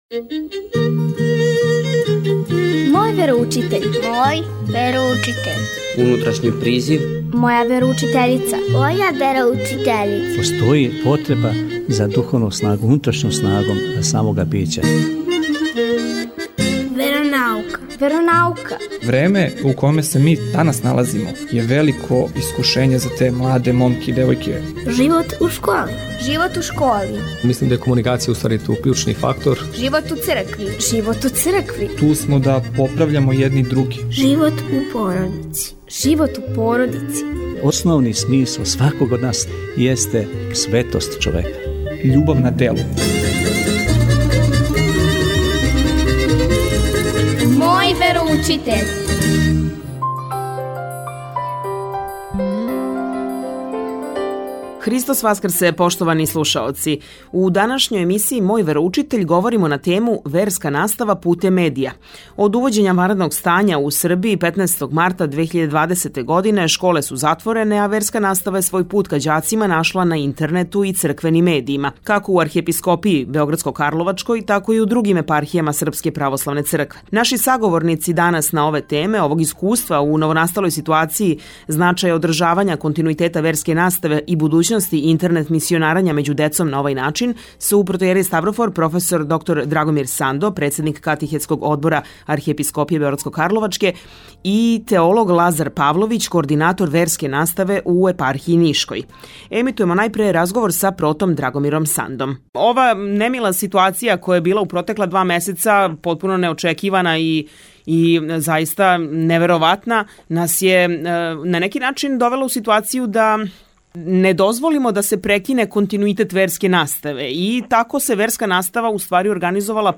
Од увођења ванредног стања у Србији, 15. марта 2020. године, школе су затворене, а верска настава је свој пут ка ђацима нашла на интернету и у црквеним медијима - како у Архиепископији београдско-карловачкој, тако и у другим епархијама СПЦ. Звучни запис емисије